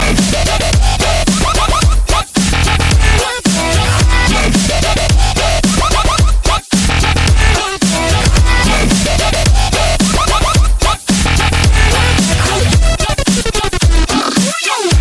Kategorien Elektronische